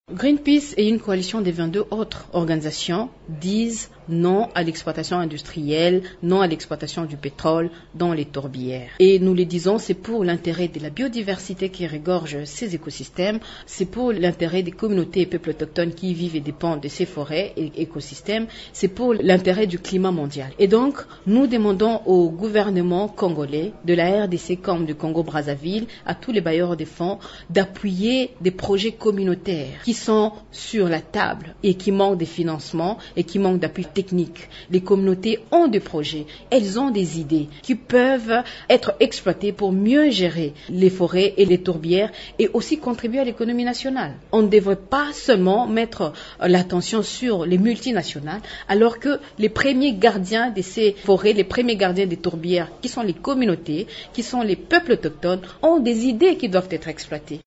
au cours d’un point de presse.